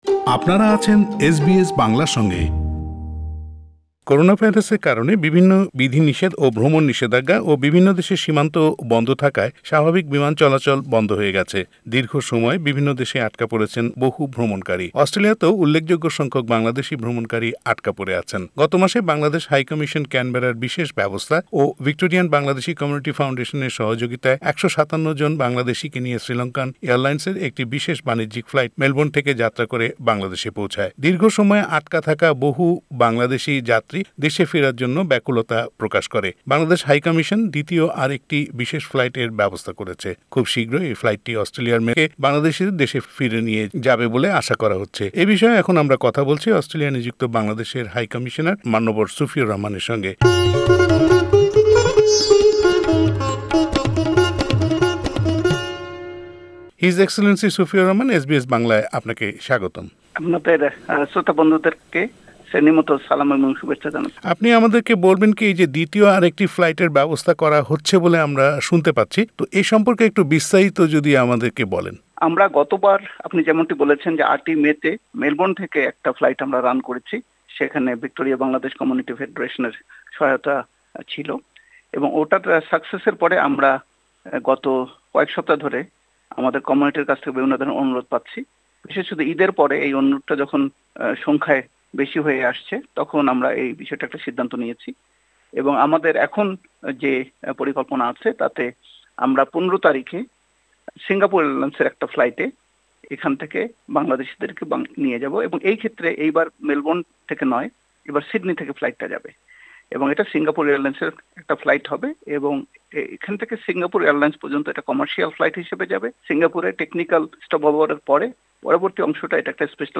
এ নিয়ে এস বি এস বাংলার সঙ্গে কথা বলছেন অস্ট্রেলিয়ায় নিযুক্ত বাংলাদেশে হাই কমিশনের হাইকমিশনার মান্যবর সুফিউর রহমান।